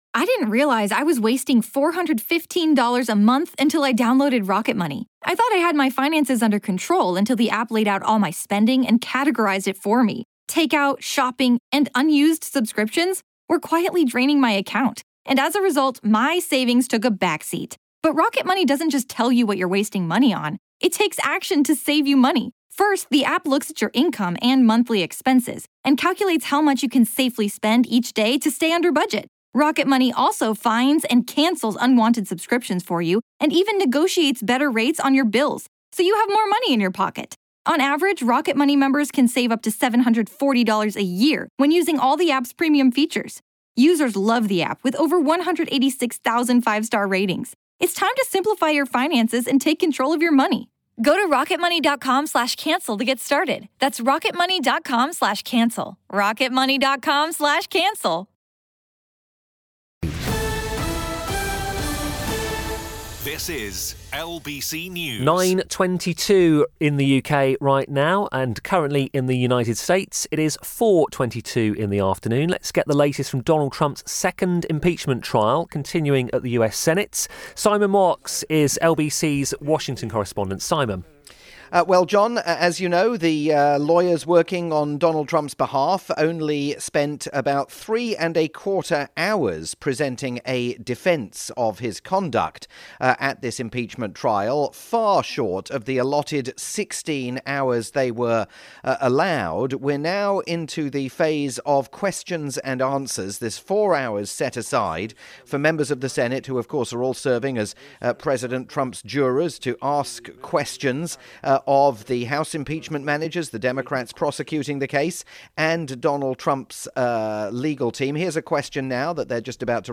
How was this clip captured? live report on the latest developments in Donald Trump's second impeachment trial for LBC News